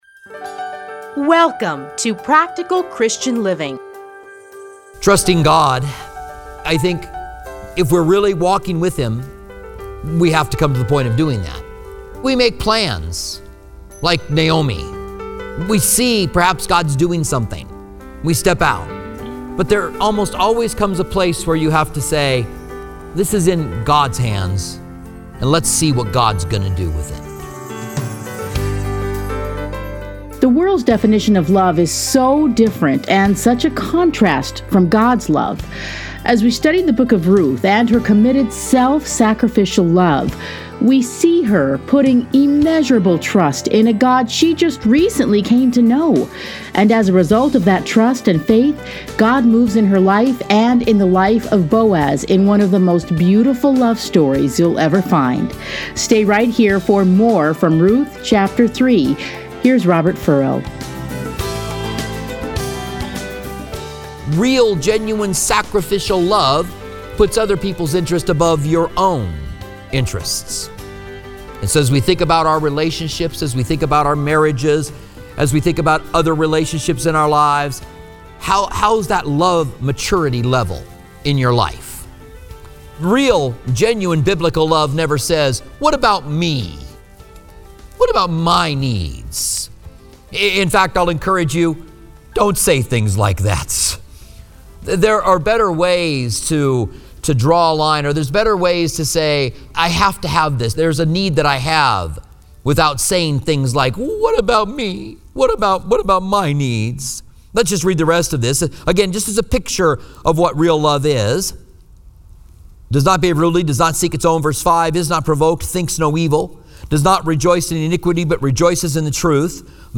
Listen here to a teaching from Ruth.